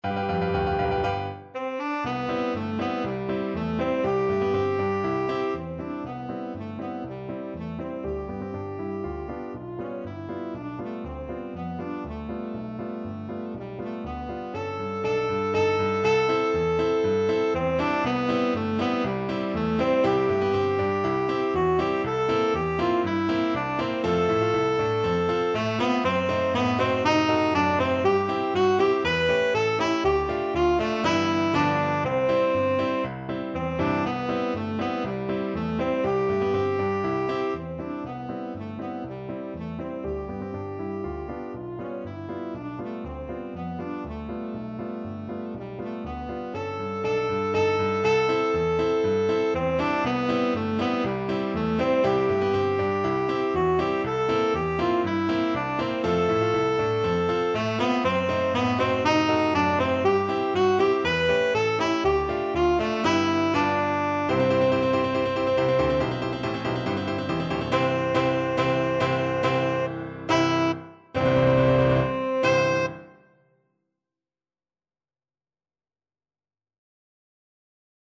2/4 (View more 2/4 Music)
Tempo di Marcia =120
Classical (View more Classical Tenor Saxophone Music)